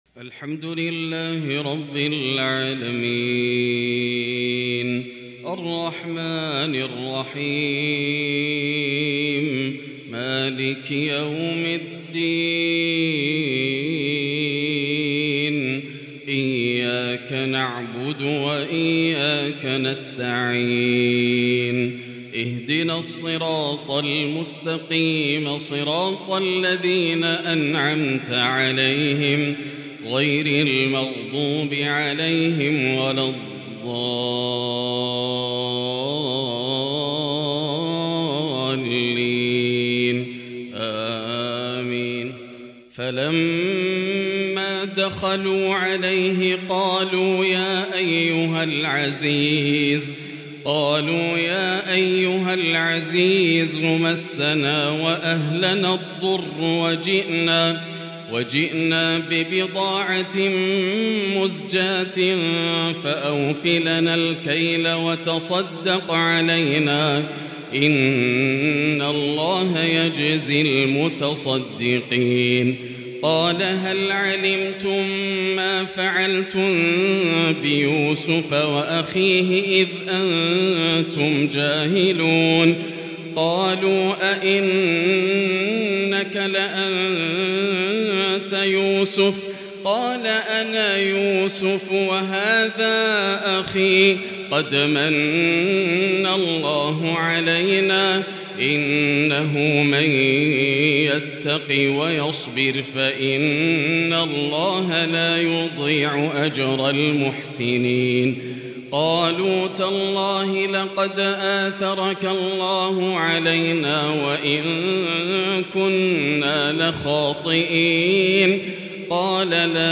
تلاوة في غاية الجمال لخواتيم سورة يوسف (88-111) - فجر الخميس 7-5-1444هـ > عام 1444 > الفروض - تلاوات ياسر الدوسري